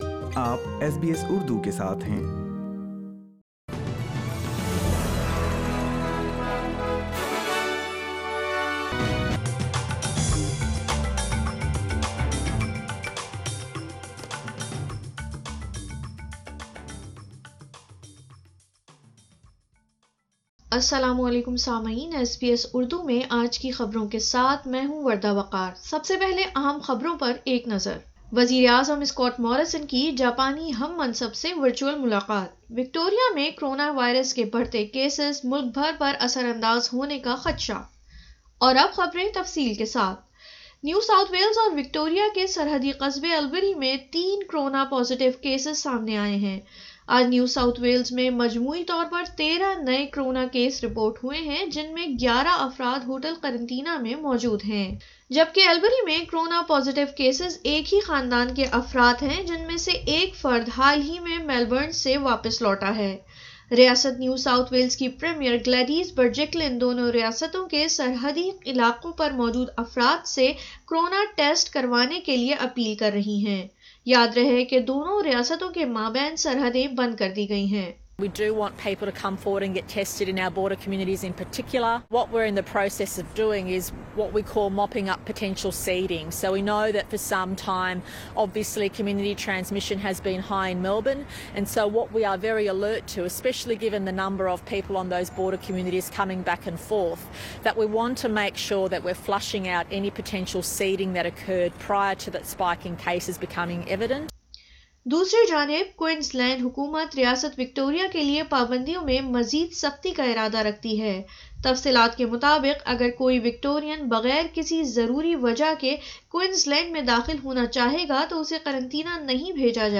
اردو خبریں 09 جولائی 2020